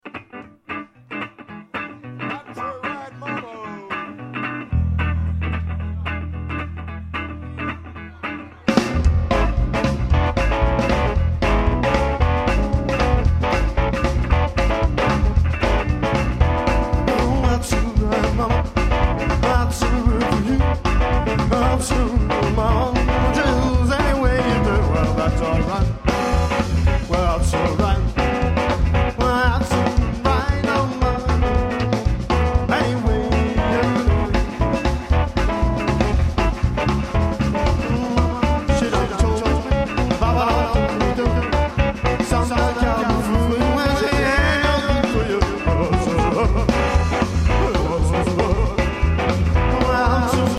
kosketinsoitin & laulu
kitara tai basso & laulu
rummut